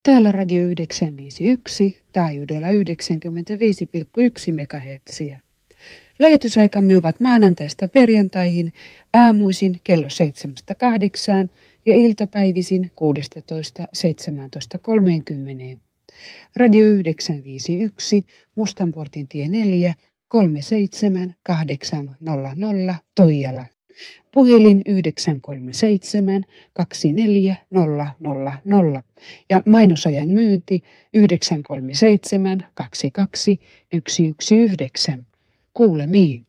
Radio 951:n promo vuodelta 1985.
Artikkelissa olevien ääninäytteiden laadun parantamisessa on käytetty tekoälysovellusta.